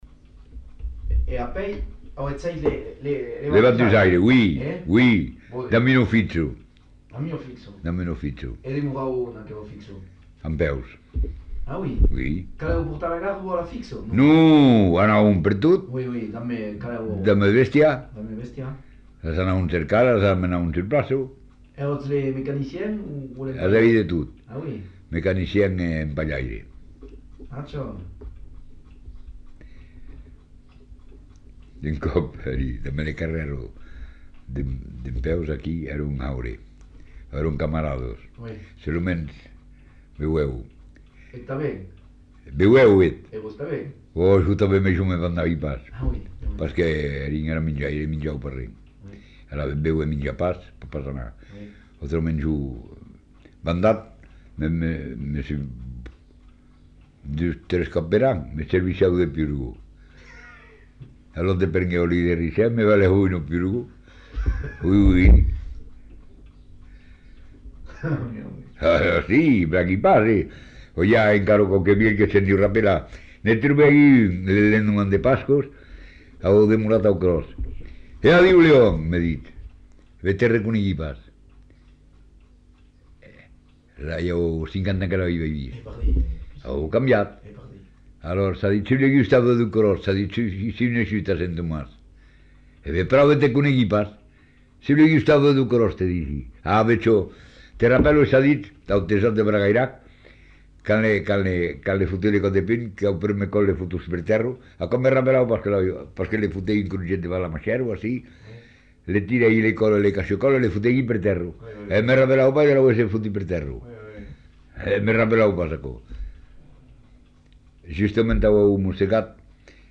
Genre : récit de vie
[enquêtes sonores]